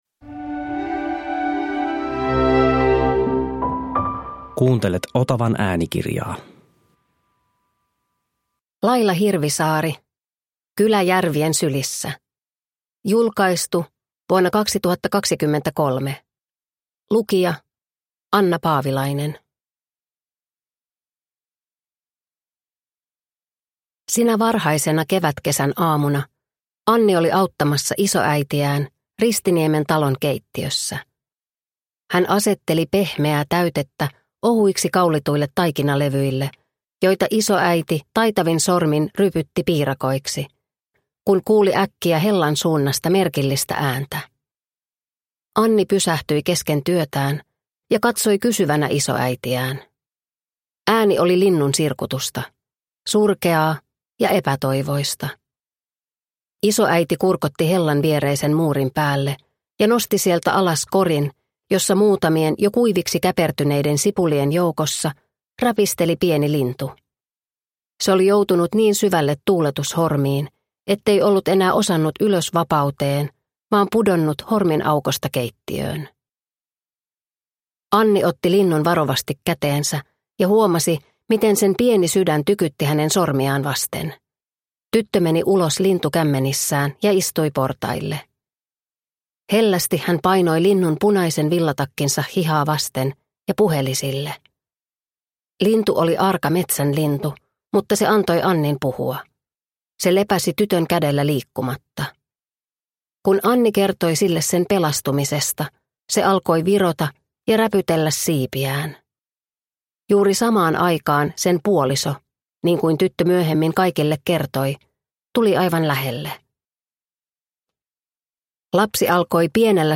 Kylä järvien sylissä – Ljudbok – Laddas ner